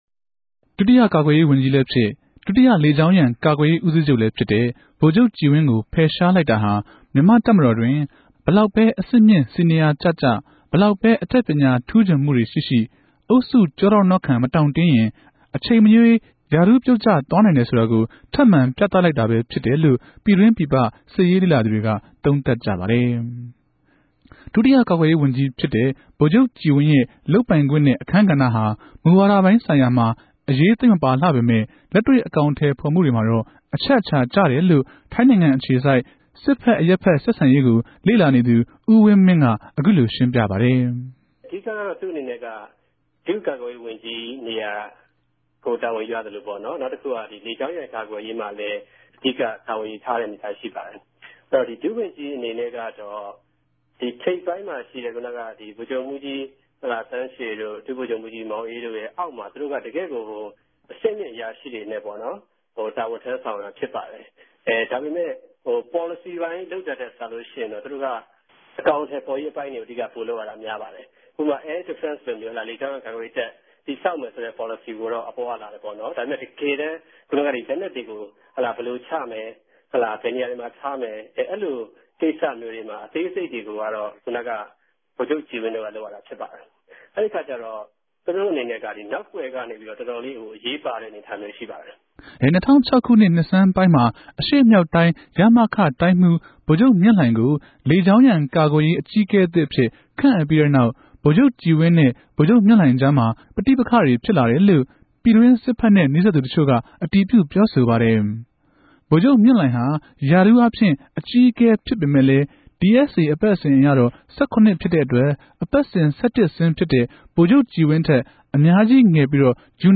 ဆက်သြယ်မေးူမန်း္ဘပီး သုံးသပ်တင်ူပထားတာကို နားထောငိံိုင်ပၝတယ်။